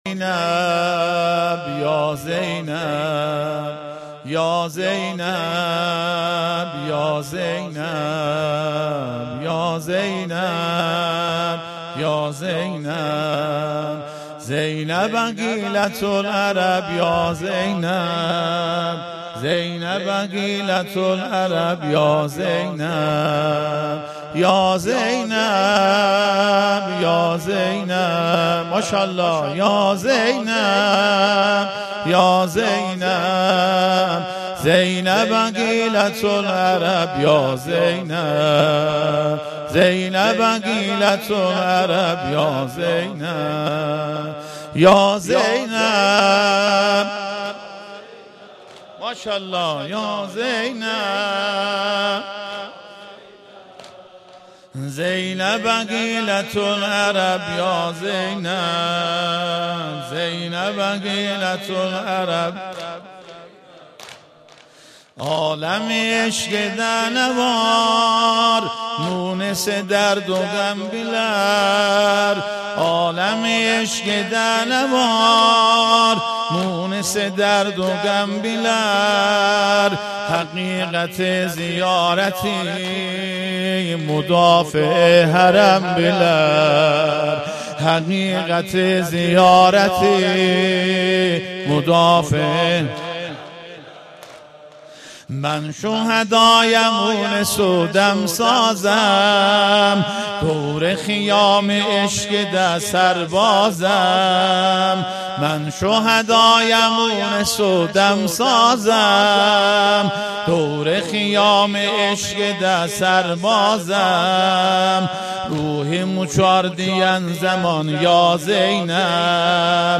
نوحه‌خوانی
برچسب ها: زنجان ، نوحه خوانی ، زینب ، نوحه ترکی